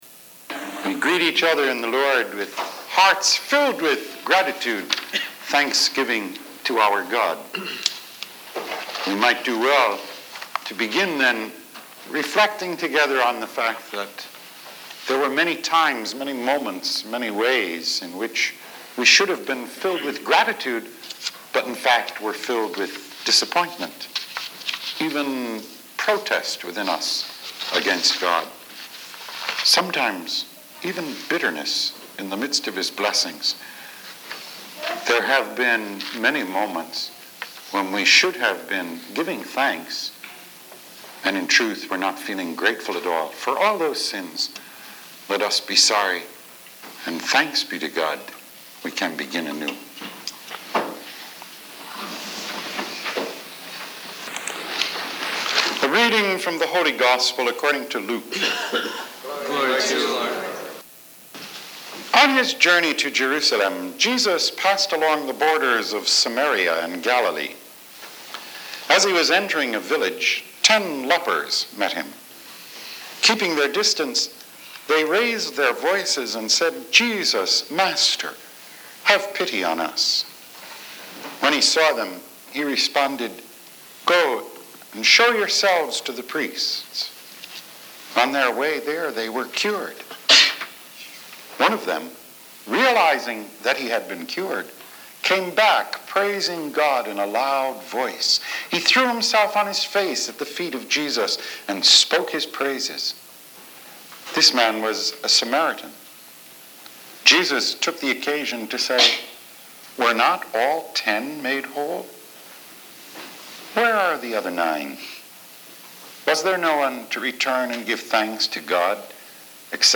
Thanksgiving « Weekly Homilies